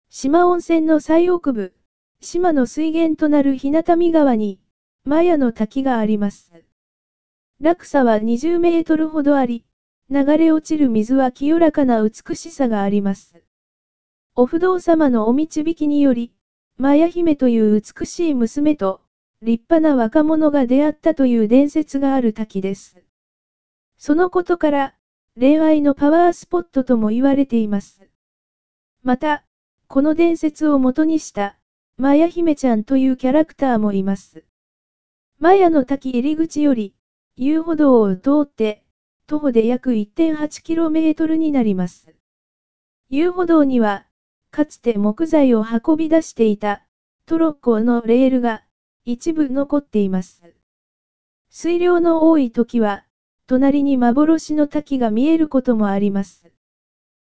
Tweet Pocket 摩耶の滝 音声案内: